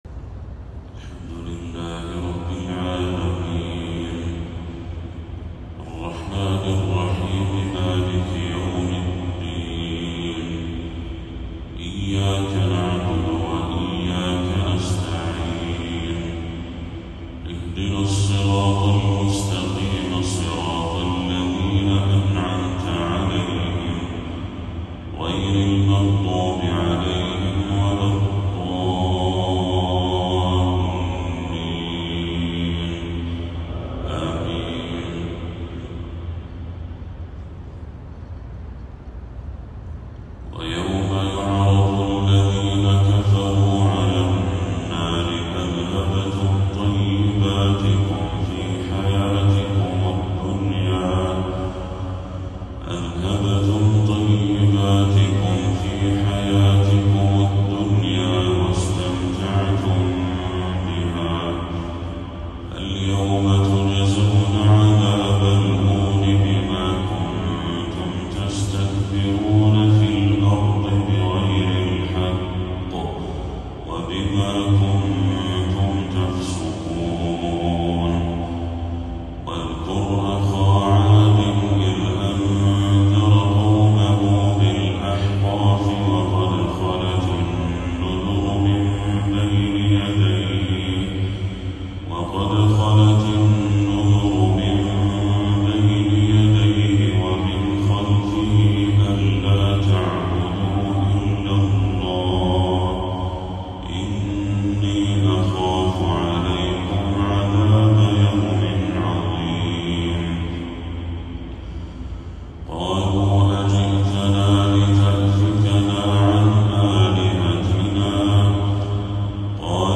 تلاوة خاشعة لخواتيم سورة الأحقاف
فجر 12 ربيع الأول 1446هـ